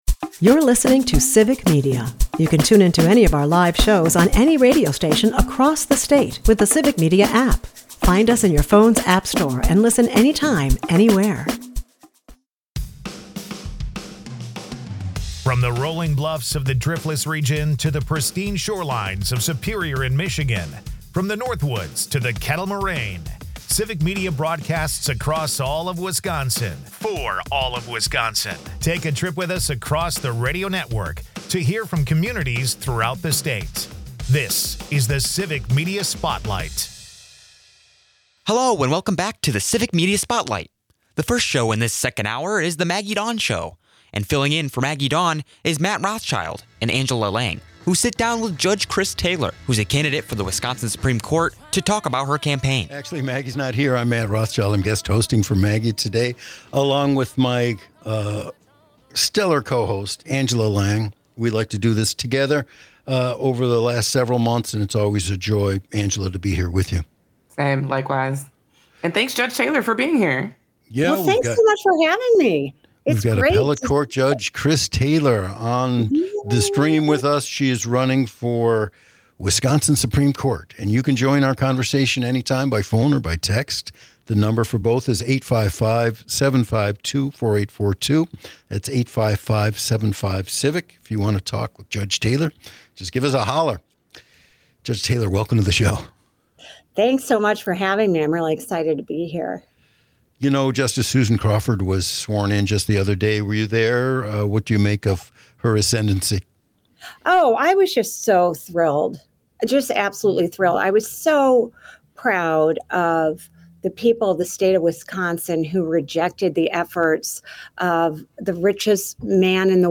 The episode wraps up with a lively chat about the dining gem Tornado Club and Madison's quirky National Mustard Museum.